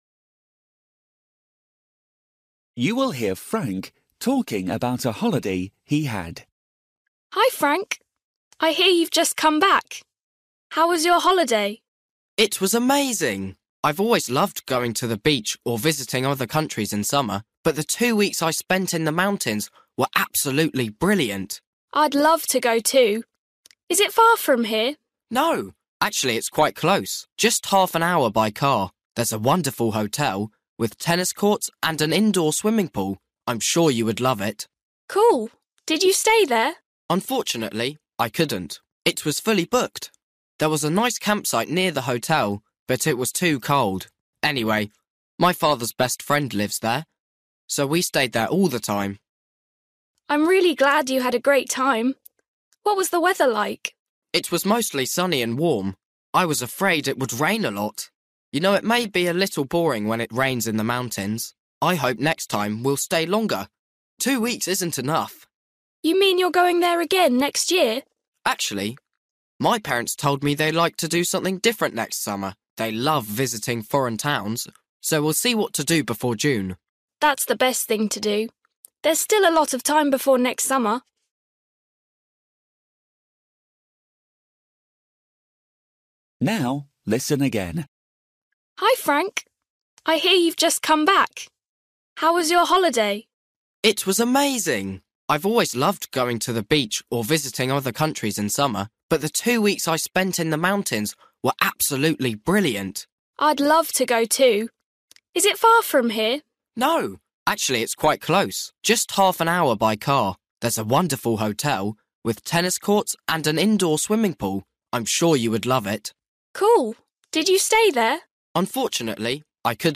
Bài tập trắc nghiệm luyện nghe tiếng Anh trình độ sơ trung cấp – Nghe một cuộc trò chuyện dài phần 31